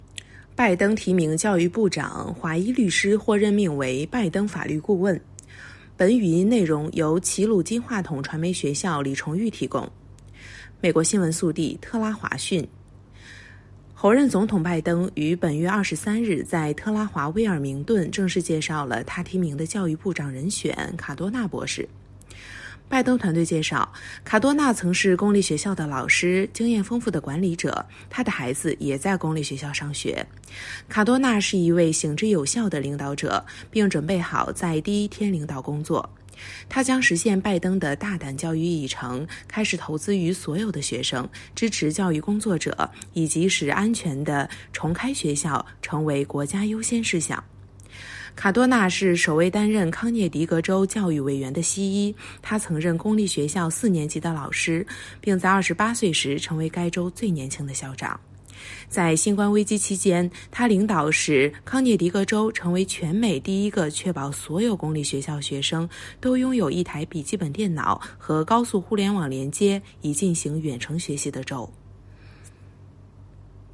【听新闻学播音】拜登提名教育部长